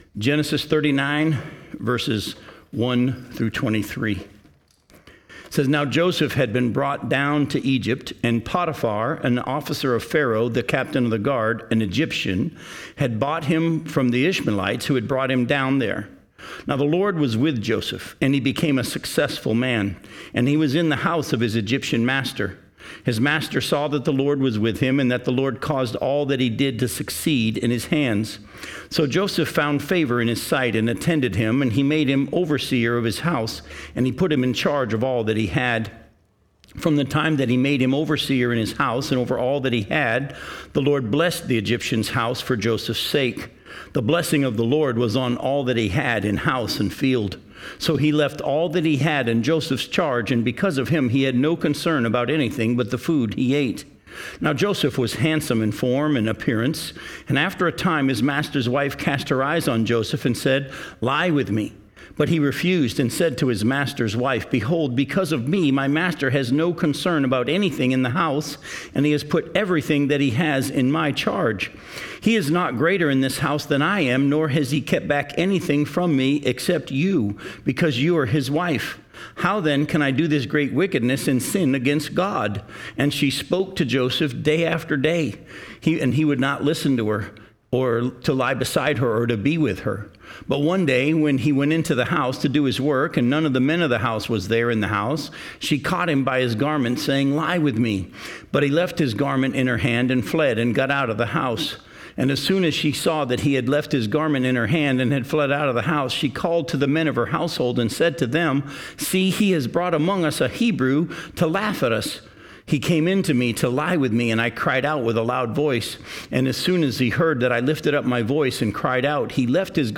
Justapreacher Ministries :: Genesis Bible Study (Wednesday Night)